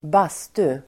Ladda ner uttalet
Uttal: [²b'as:tu]